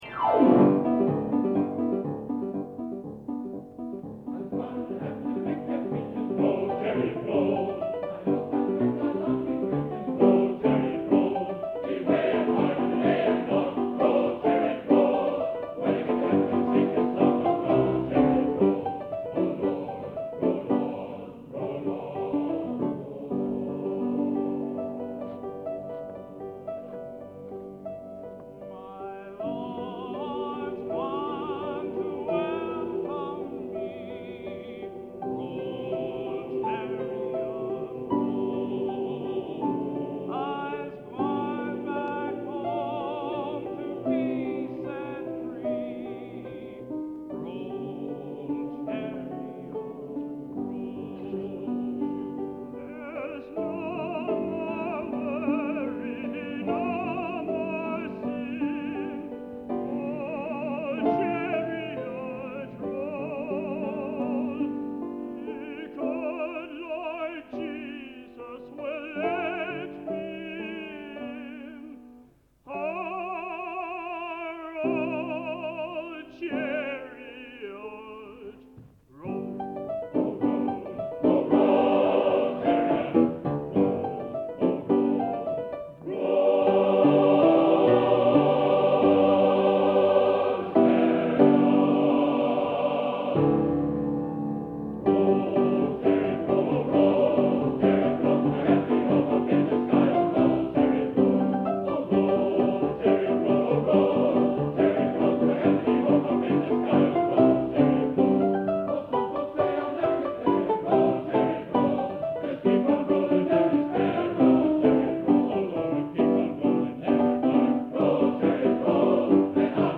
Collection: Plymouth, England
Location: Plymouth, England